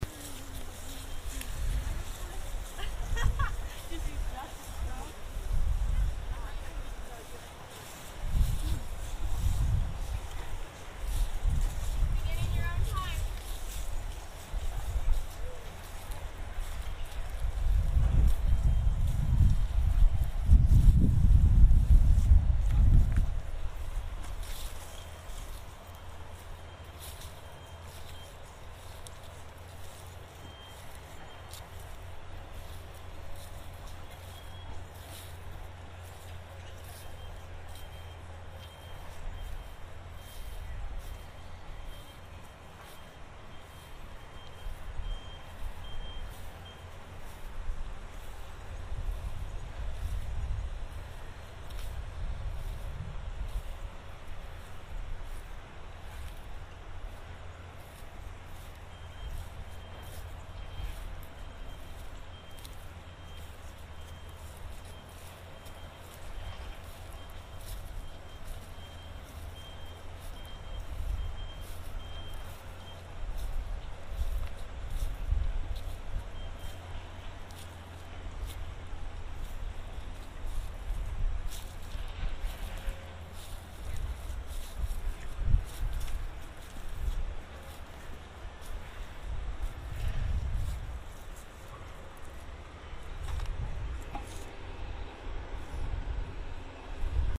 Grove ambient 30.mp3 Observer laughs, observer comments
" steps in leaves, beeps